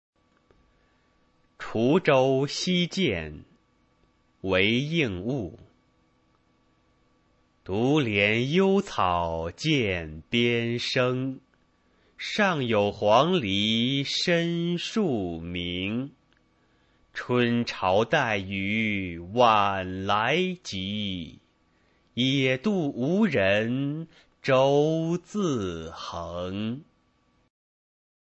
韦应物《滁州西涧》原文和译文（含赏析、朗读）